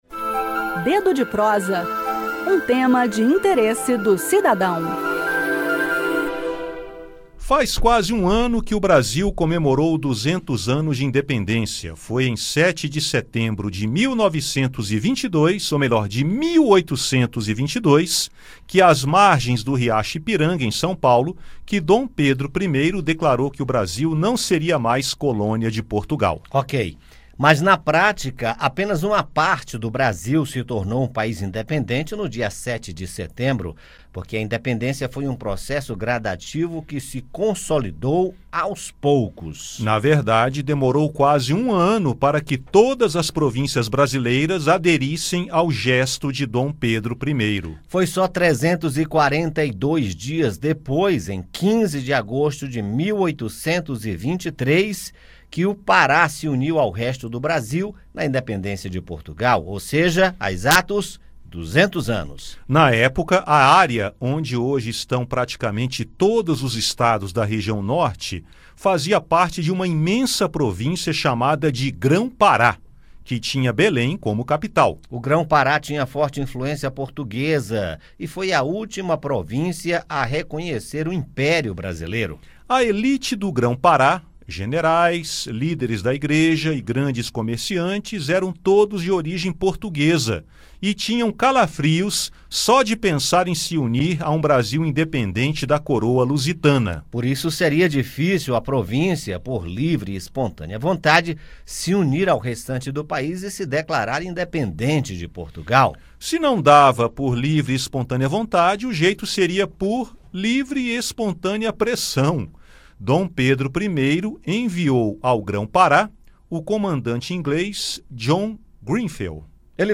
A Independência foi um processo gradativo, que se consolidou aos poucos e demorou quase um ano para que todas as províncias brasileiras aderissem ao gesto de Dom Pedro I. O estado do Pará se uniu à Independência apenas 342 dias depois, em 15 de agosto de 1823, ou seja, há exatos 200 anos. Saiba mais no bate-papo.